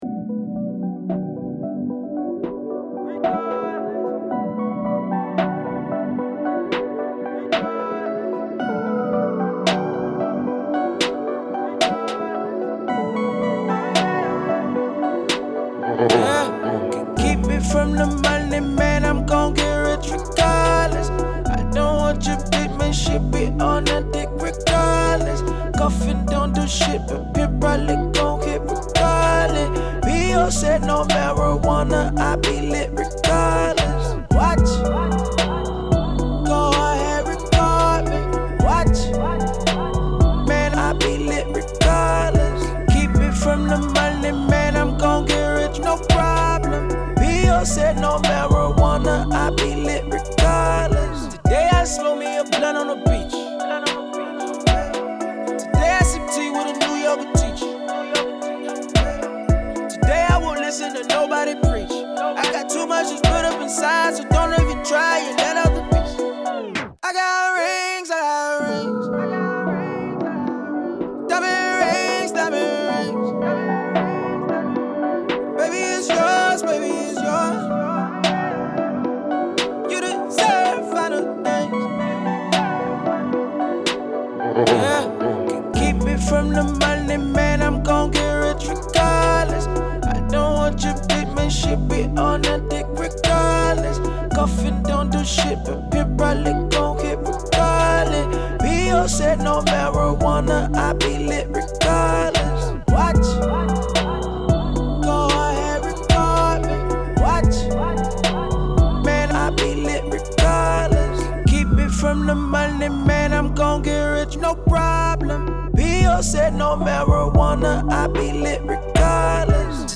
Urban Music
It's an urban record so the design should keep this in mind.
• The singer in the song is a hippy black guy.